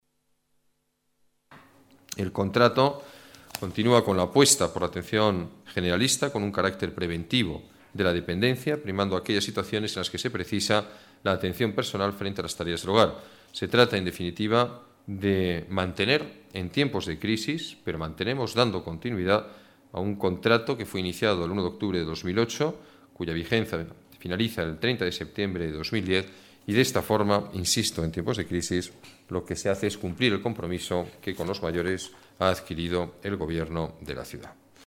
Nueva ventana:Declaraciones alcalde, Alberto Ruiz-Gallardón: presupuesto destinado ayuda a domicilio